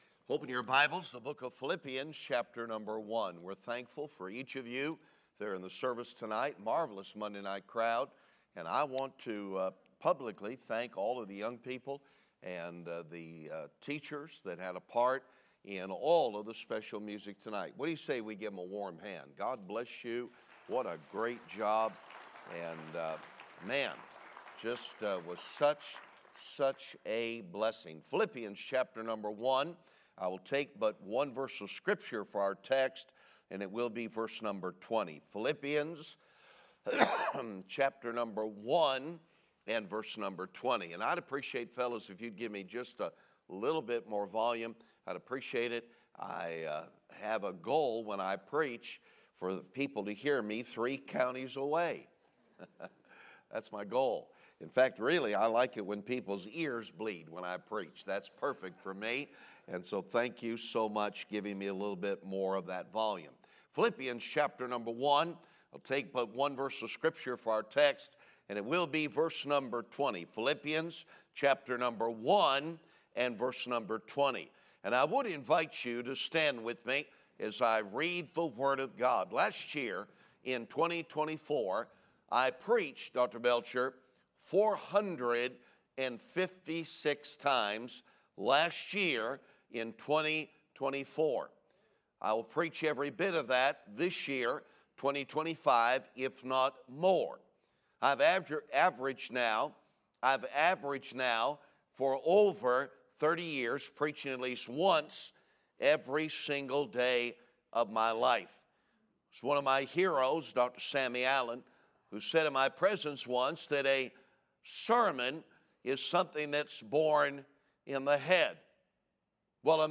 Special Service